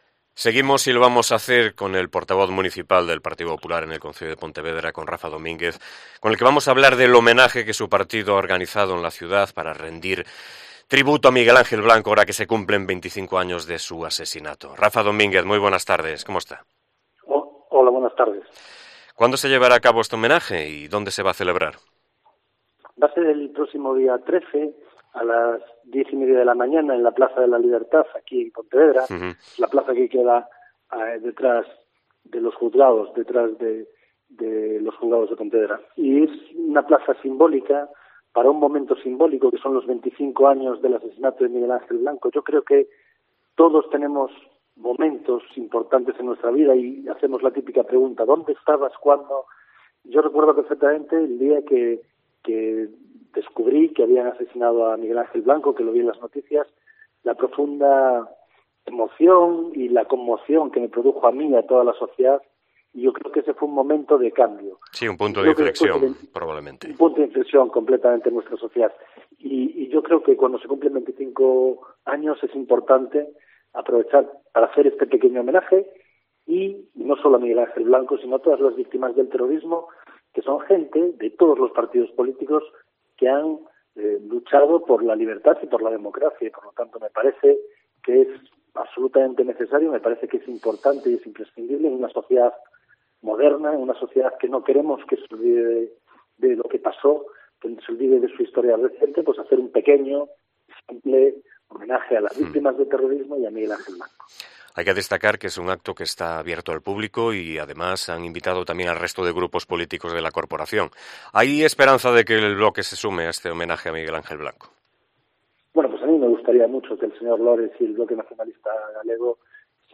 Entrevista con Rafa Domínguez, presidente del PP de Pontevedra